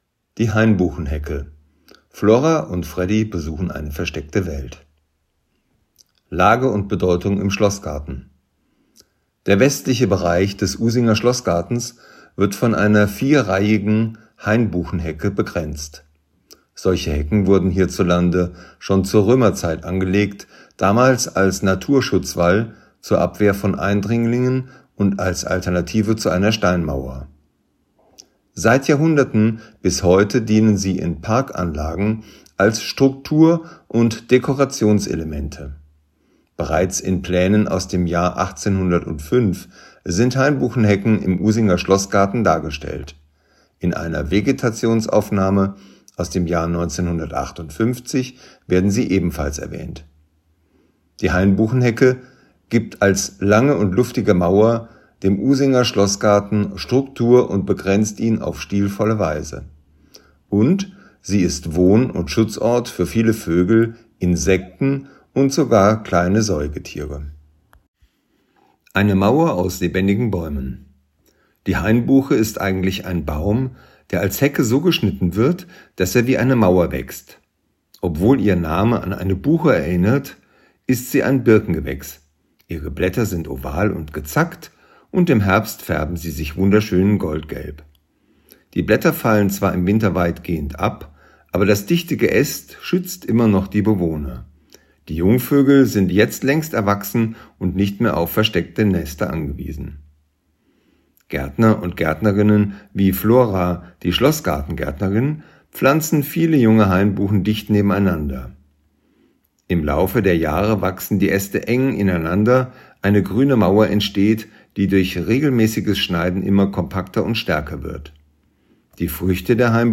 Wer nicht alle Schilder an den Stationen im Schlossgarten lesen möchte oder kann, kann sie sich hier einfach vorlesen lassen.